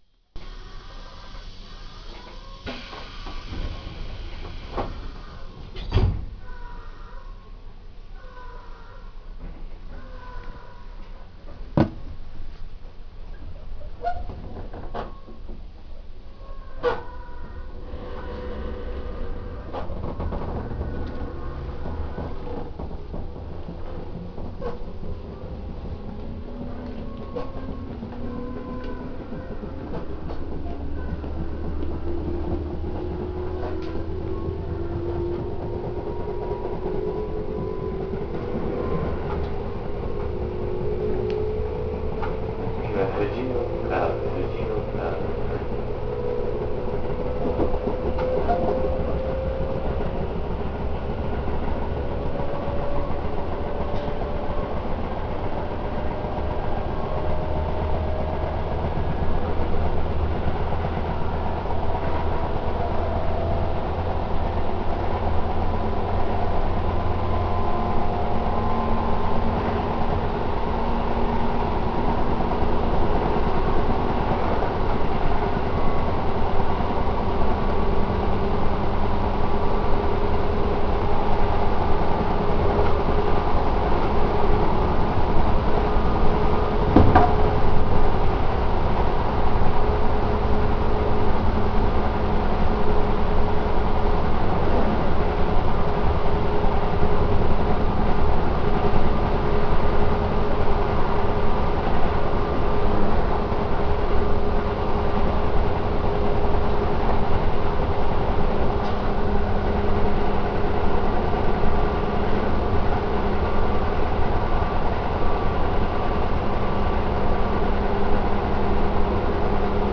・6050系走行音
【日光線】静和→藤岡（6分46秒：2.15MB）
とはいえ、抵抗制御なので、8000系と走行音は大差ありません…が、8000系と異なり電制があるので、減速時にもモーター音が聞こえます。
扉などは当時製造されていた10000系統に準じているため、動作時の音は8000系列と比べていくらか静かになっています。